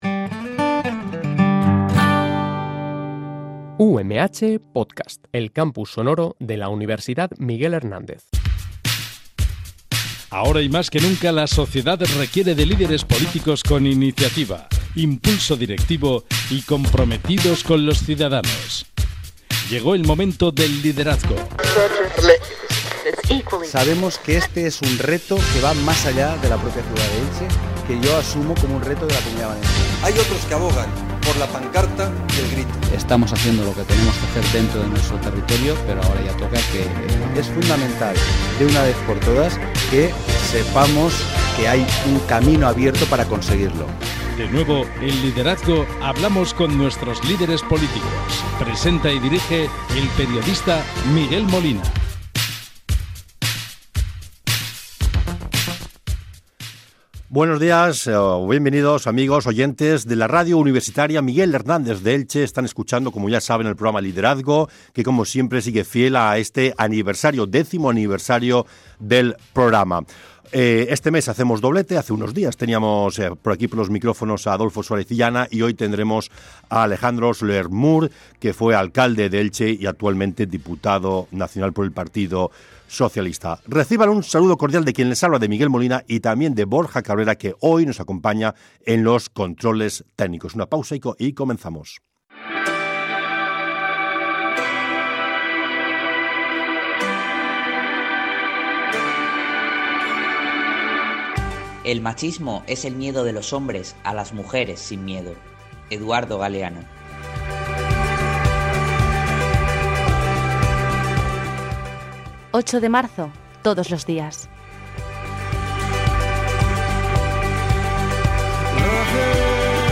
El diputado nacional del PSOE Alejandro Soler ha pasado por los micrófonos de Liderazgo para comentar la actualidad política tanto a nivel local, autonómica como nacional.